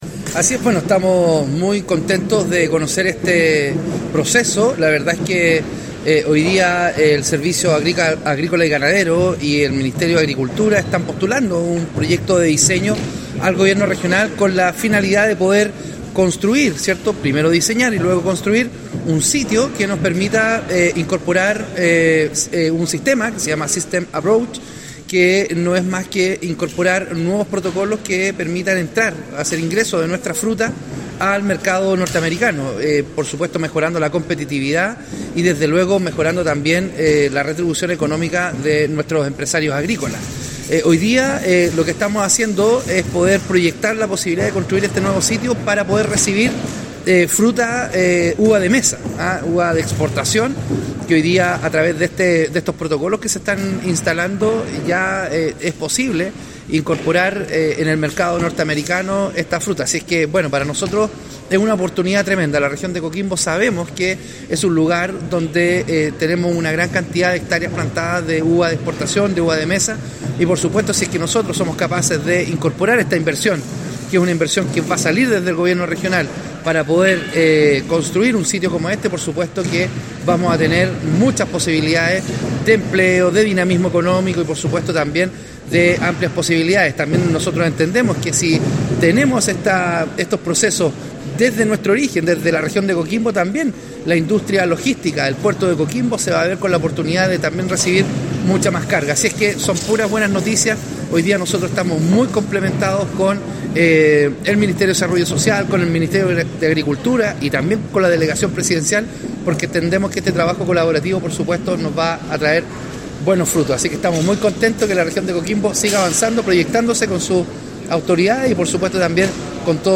EXPORTACIONES-SAG-Wladimir-Pleticosic-Gobernador-Regional-s.mp3